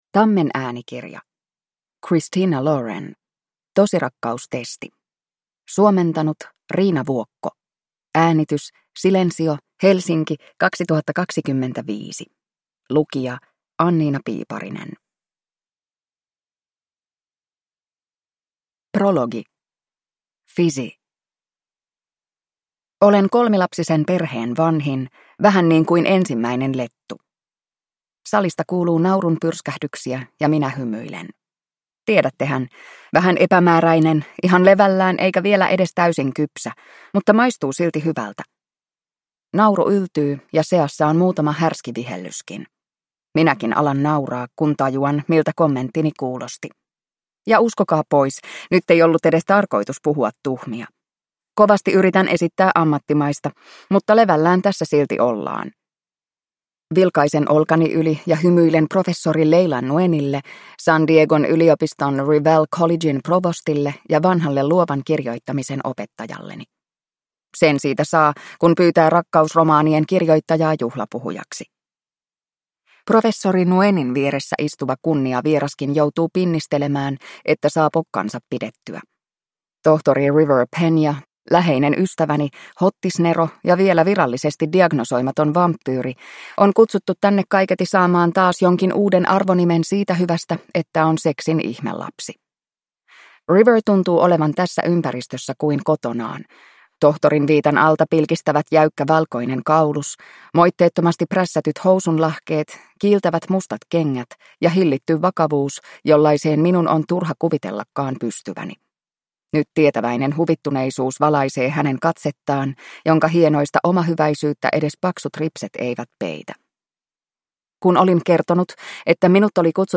Tosirakkaustesti – Ljudbok